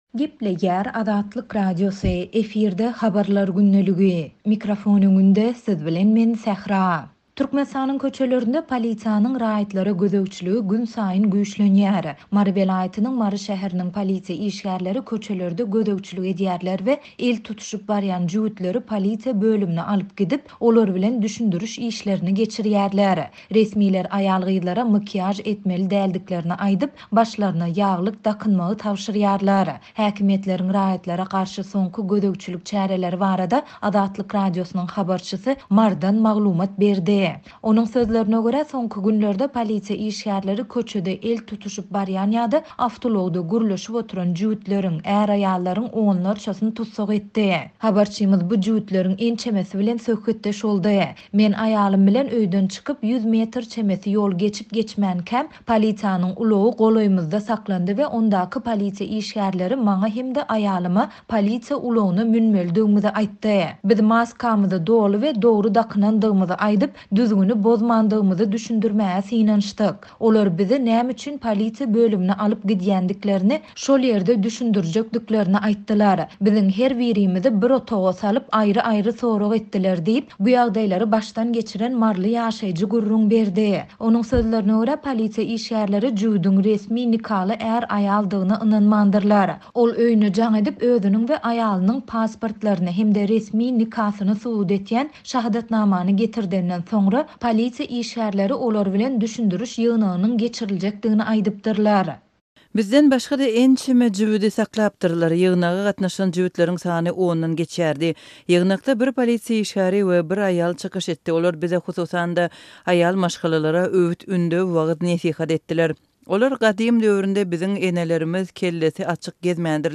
Häkimiýetleriň raýatlara garşy soňky gözegçilik çäreleri barada Azatlyk Radiosynyň habarçysy Marydan habar berdi.